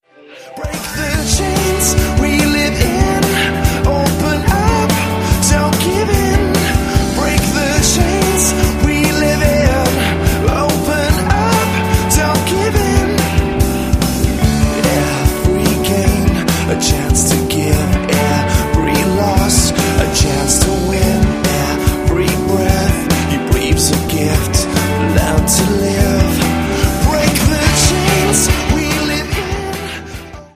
christlichen Rockbands
kraftvollen, erdigen Rock
• Sachgebiet: Rock